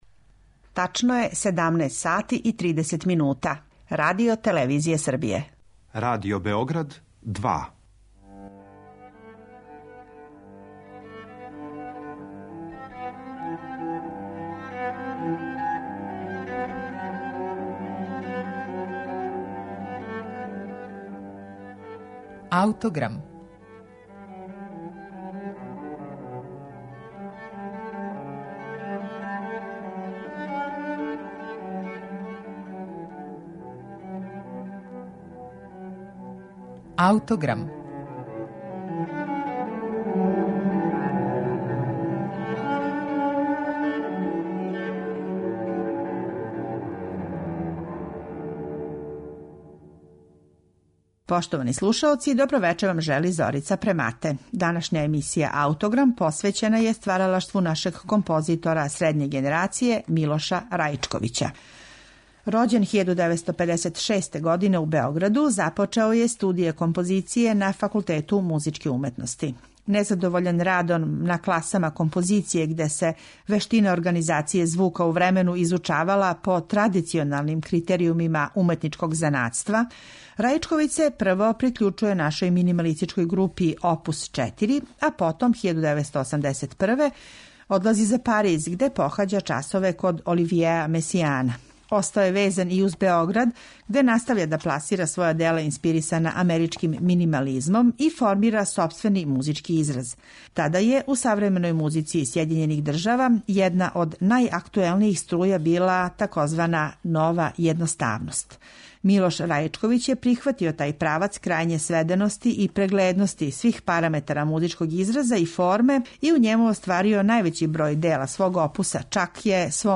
Његово стваралаштво биће сагледано кроз једно његово рано дело, настало крајем девете деценије прошлог века, "Летећи трио" за виолину, виолончело и клавир.